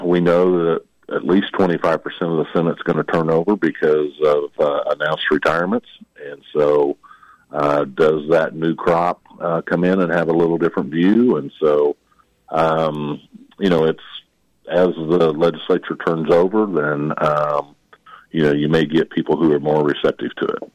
Longbine’s appearance on KVOE’s Morning Show followed an appearance by retiring 76th District Representative Eric Smith of Burlington Tuesday.